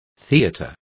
Complete with pronunciation of the translation of theaters.